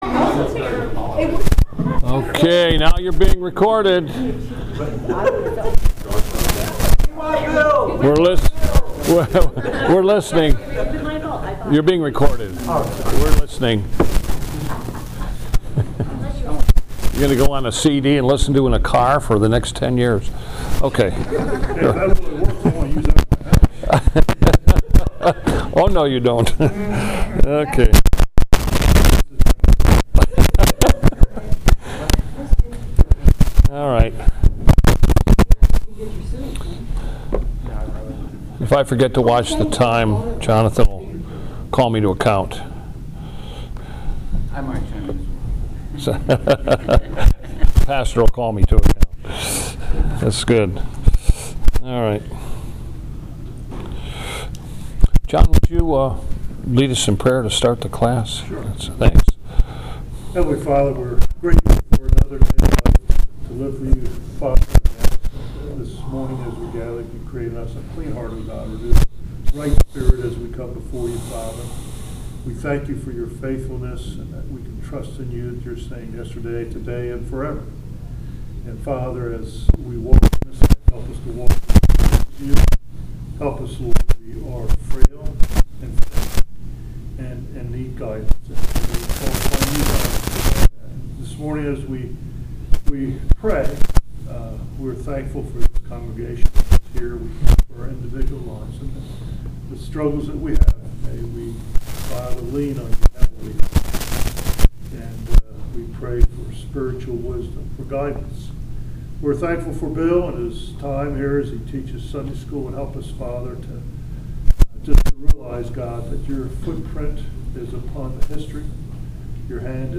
Presbyterian History – Lecture 20
Presbyterian History Service Type: Sabbath School « Presbyterian History
Presbyterian-History-Lecture-20.mp3